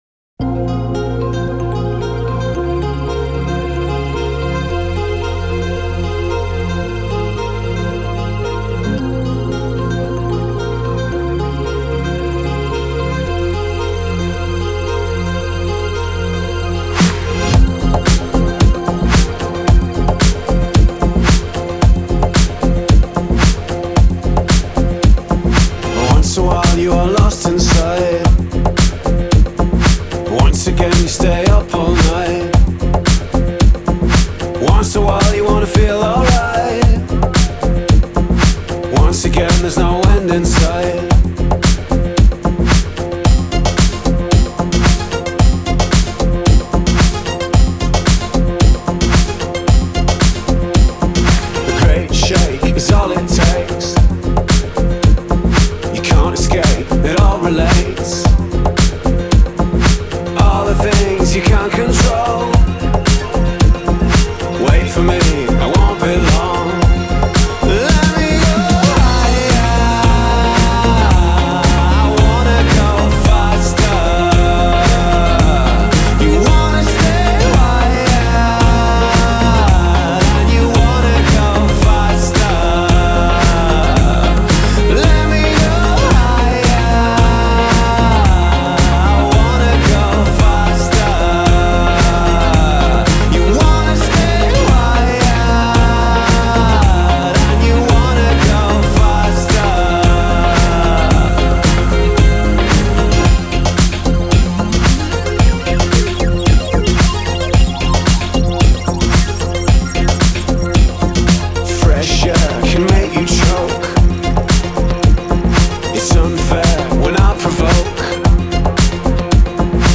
Стиль: House, Downtempo, Electronic